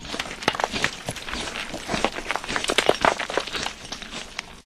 增加墓碑吞噬者音效
gravebusterchomp.ogg